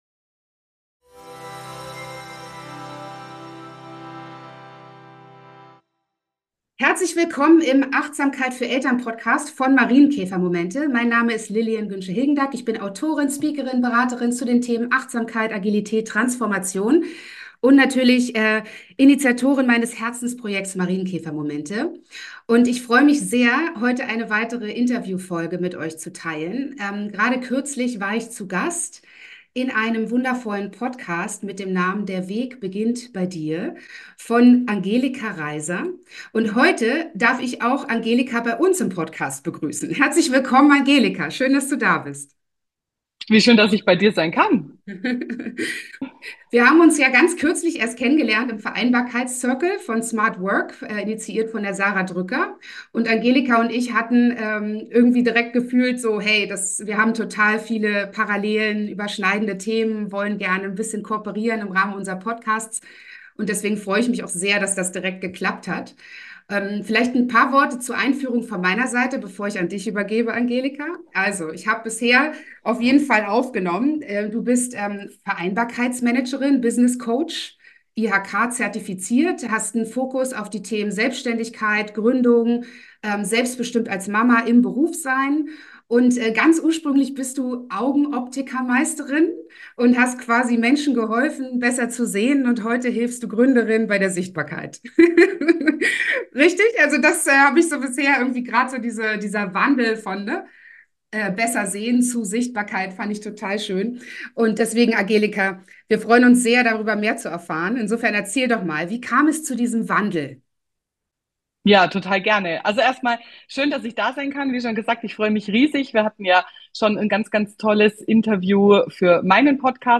im Talk